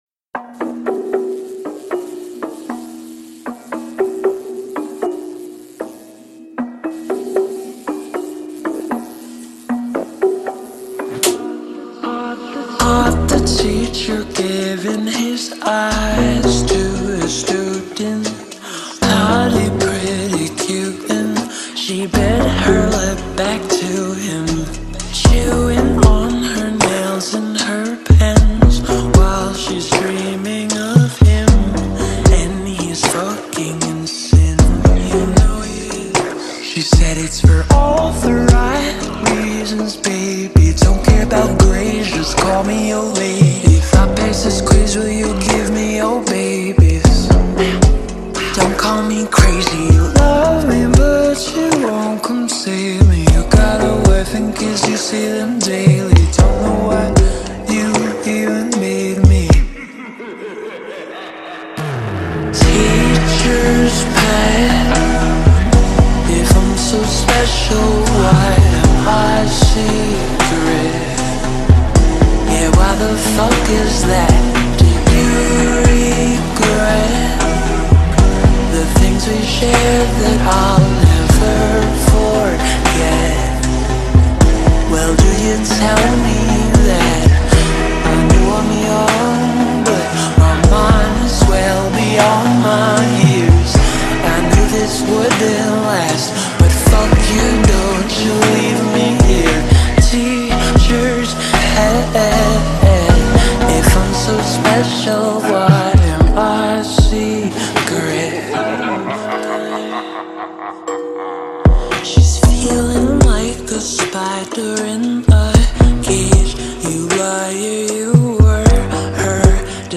s l o w e d